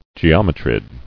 [ge·om·e·trid]